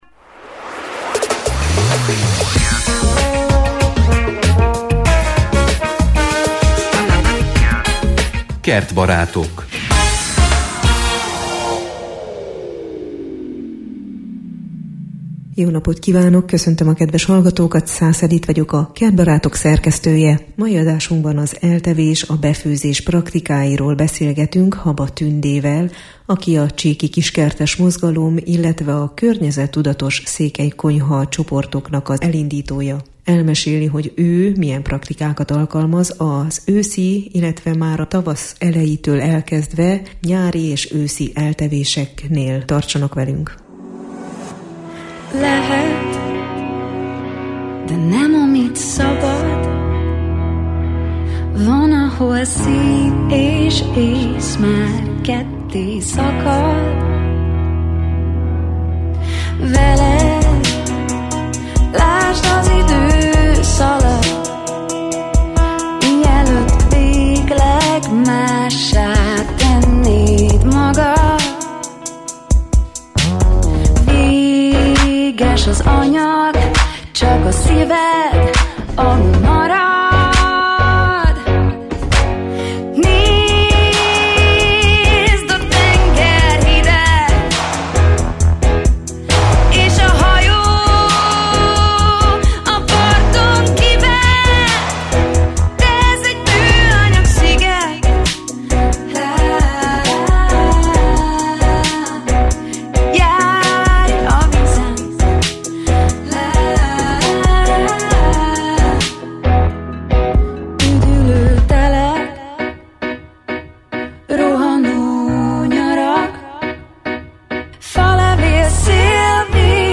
A Kertbarátok szeptember végi műsorában az őszi eltevésekről, eltevési praktikákról beszélgetünk: befőzés, aszalás, fagyasztás, szárítás.